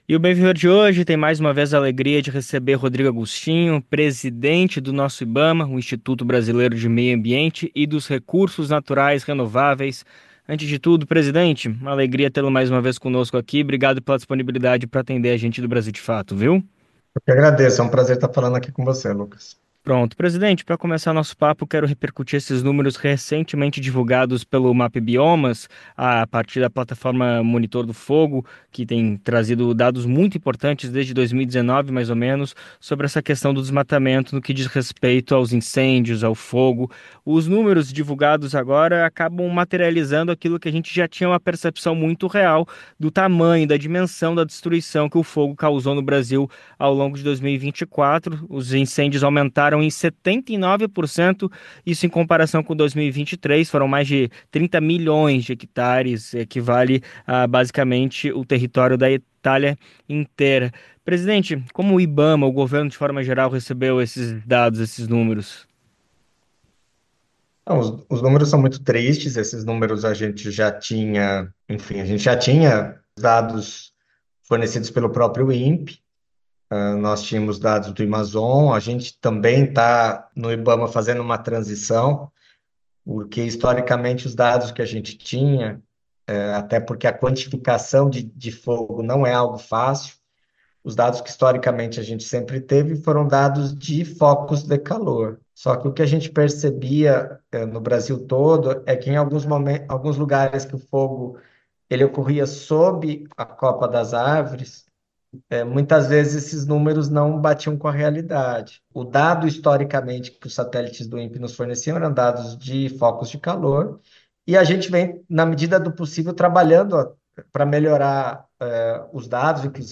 Ao programa Bem Viver, da Rádio Brasil de Fato, o presidente do Instituto, Rodrigo Agostinho, comentou a percepção do órgão sobre o novo plano.